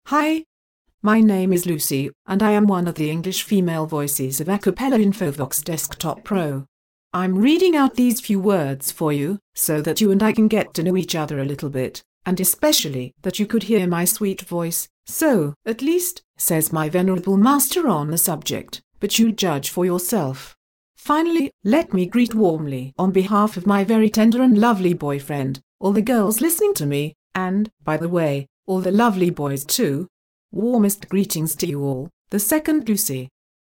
�couter la d�monstration de Lucy, voix f�minine anglaise d'Acapela Infovox Desktop Pro